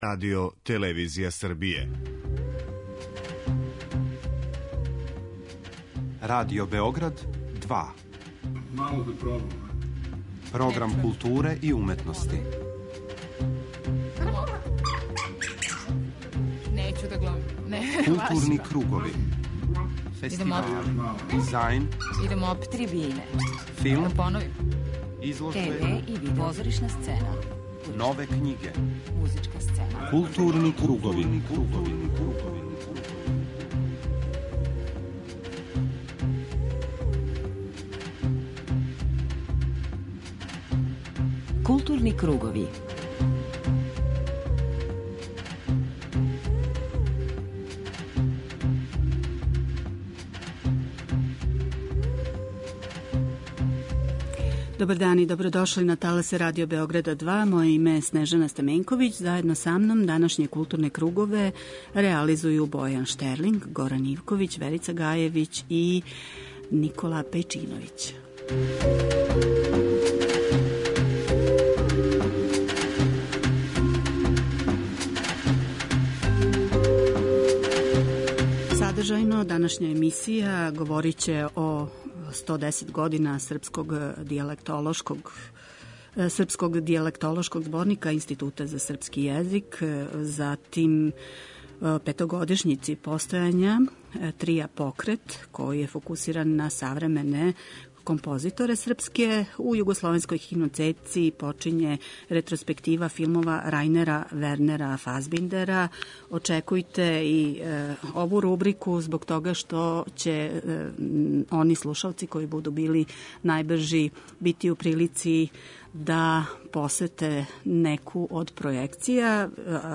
У данашњем Златном пресеку саслушаћемо аргументе актера овог догађаја.